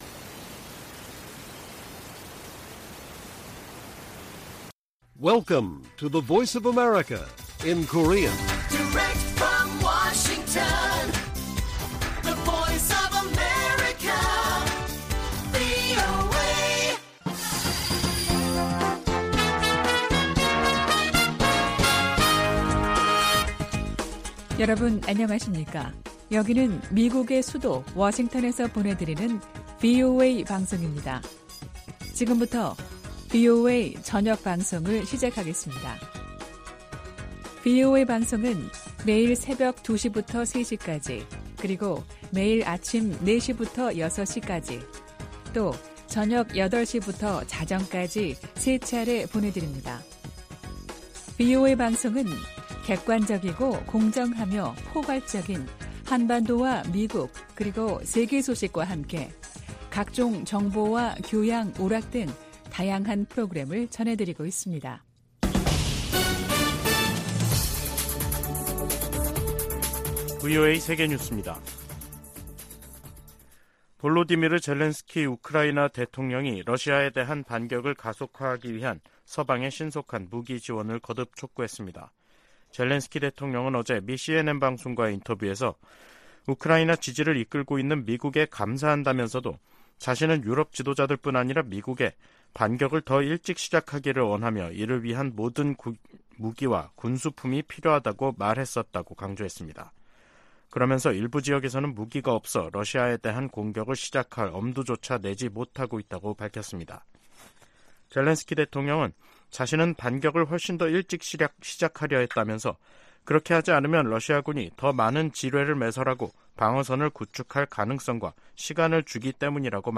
VOA 한국어 간판 뉴스 프로그램 '뉴스 투데이', 2023년 7월 6일 1부 방송입니다. 한국 군 당국이 서해에서 인양한 북한의 정찰위성을 분석한 결과 군사적 효용성이 전혀 없다고 평가했습니다. 한중 외교 당국 대화가 재개된 것은 '위험 관리'에 들어간 최근 미중 간 기류와 무관하지 않다고 미국의 전직 관리들이 진단했습니다. 중국의 반간첩법 개정안은 중국 내 탈북민 구출 활동을 봉쇄할 수 있는 악법이라고 탈북 지원단체들과 브로커들이 말했습니다.